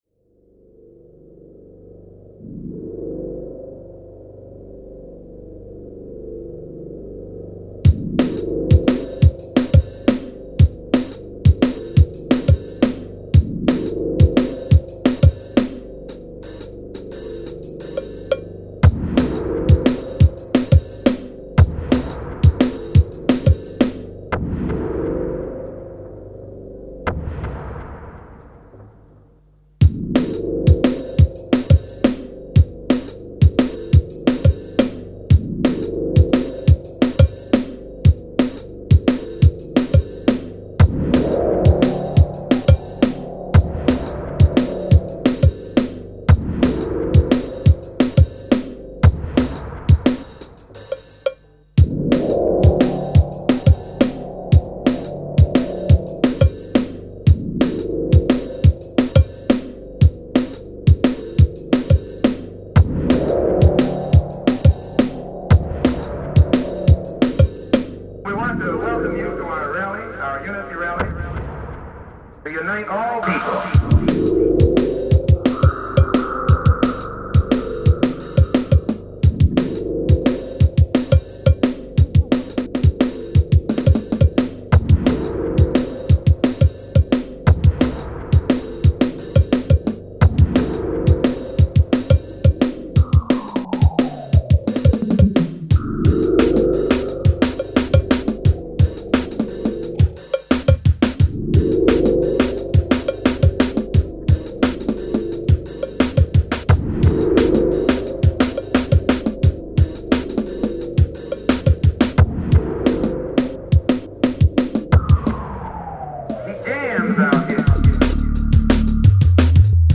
futuristic dark sounds
Techstep